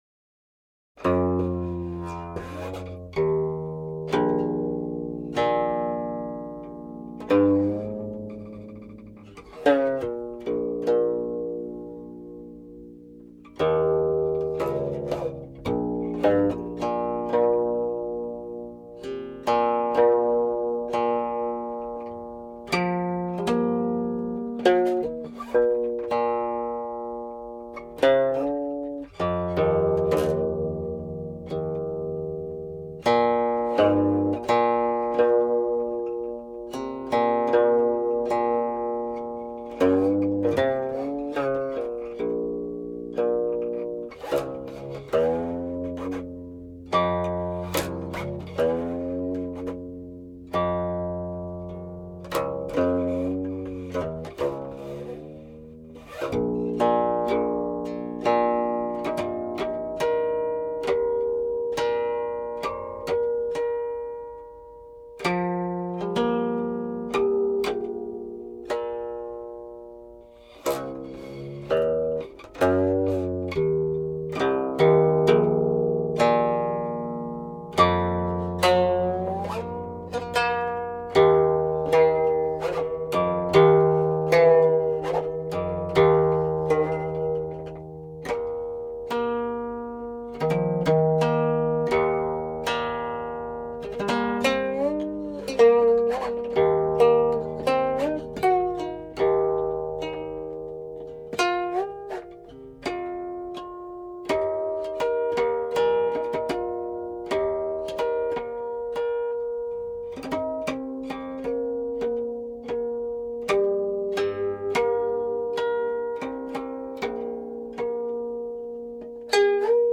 Originally undivided; here arranged into five sections according to 1585 (compare elsewhere), 20 which adds lyrics 21